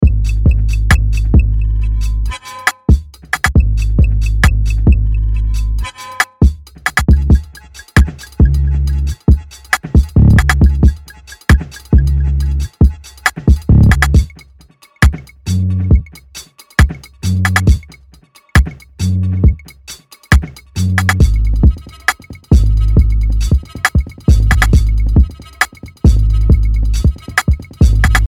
グルーヴィーなローファイ・バウンス、グリッドを超越するファンキー
・にじみ出るローファイ、遊び心、グリッドを超えるグルーブ感あふれるビート
プリセットデモ